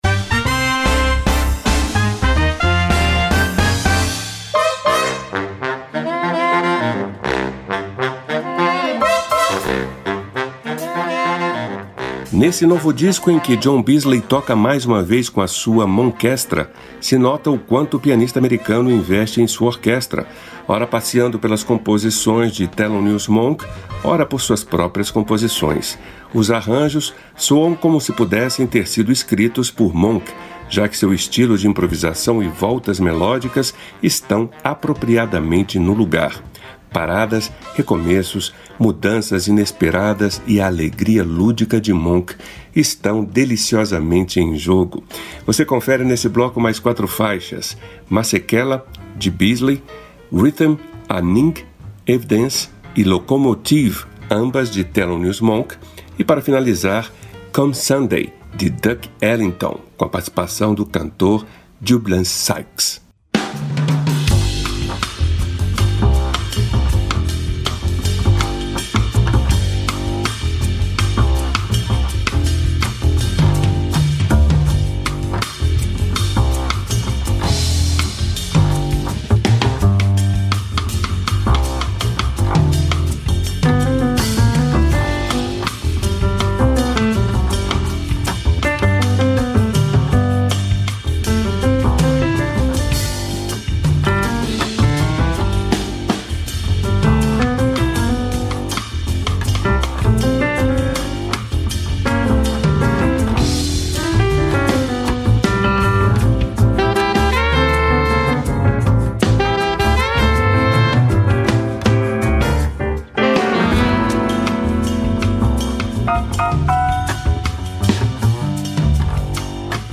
pianista americano